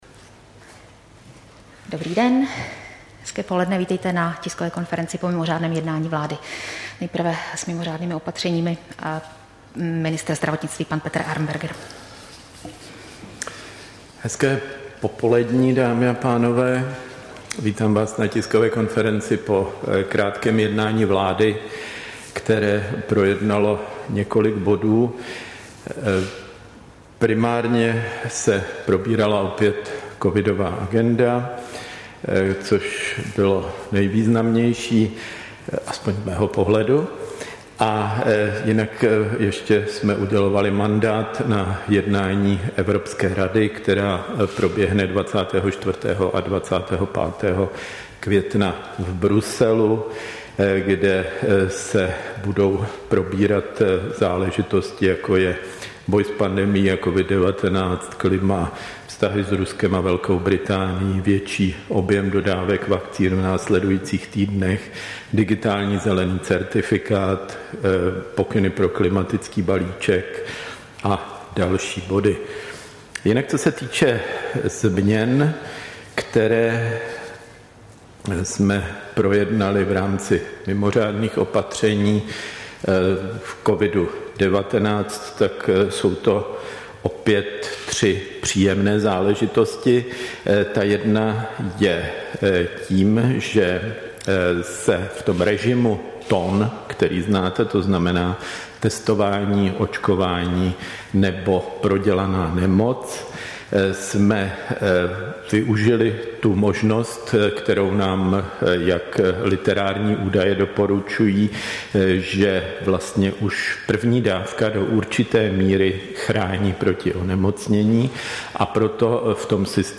Tisková konference po mimořádném jednání vlády, 21. května 2021